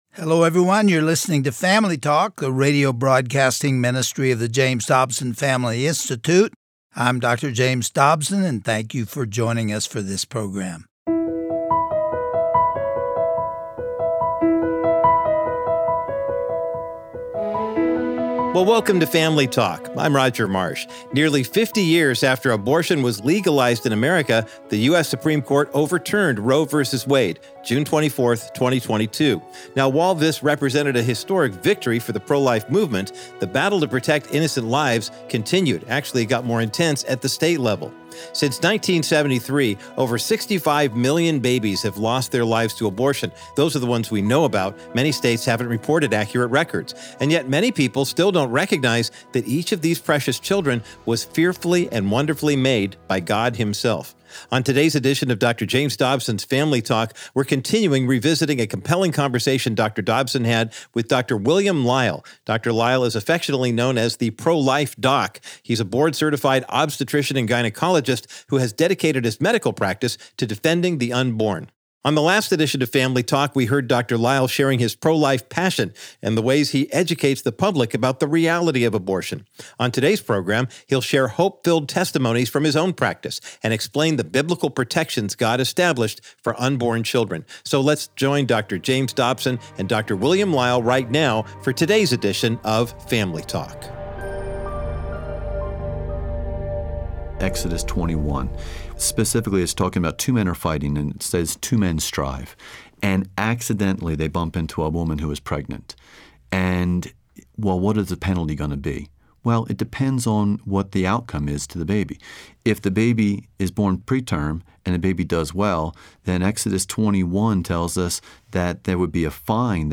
Host Dr. James Dobson